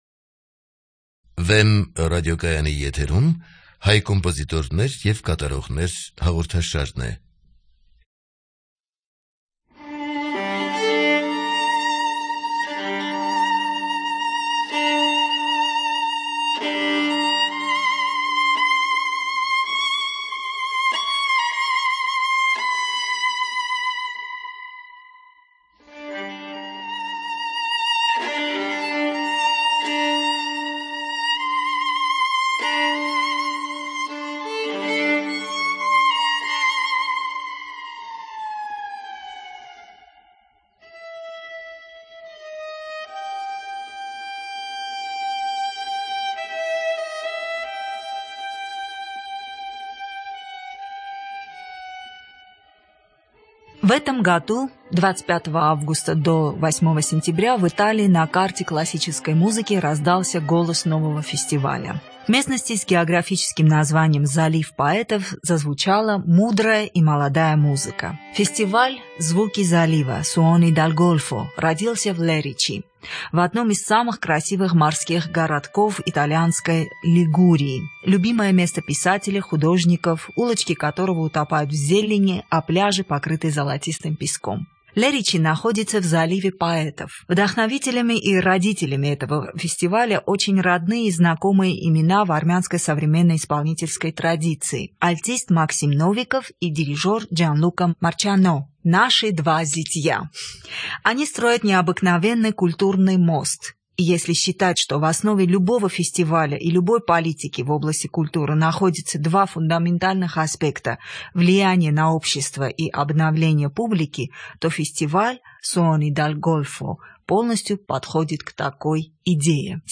Во время беседы гость представил свои впечатления от Италии и новости собственной творческой жизни.